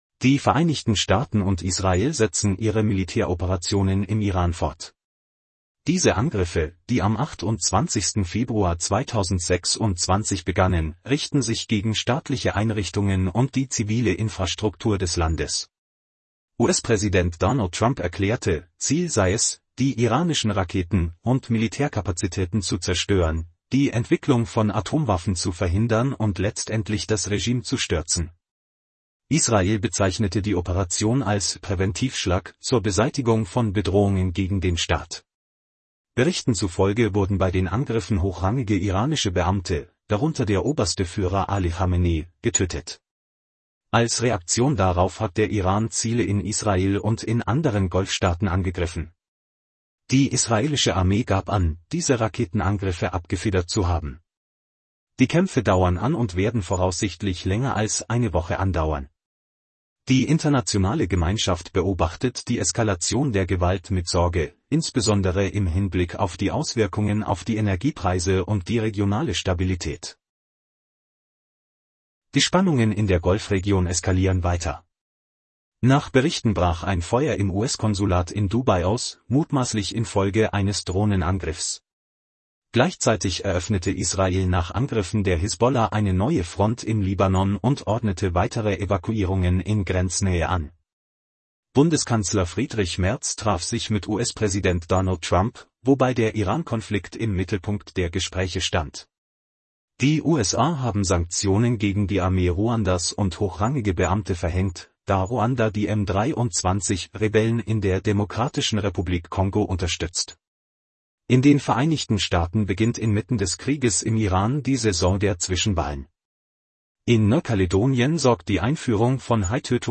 Dies ist ein Nachrichten-Podcast aus Dutzenden von Kurzberichten, zum hands-free Hören beim Autofahren oder in anderen Situationen.